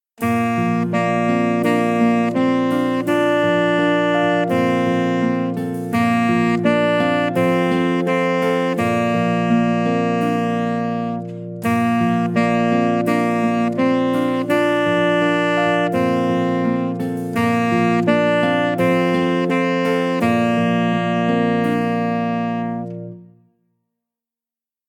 Voicing: Tenor Saxophone